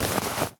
foley_object_push_pull_move_07.wav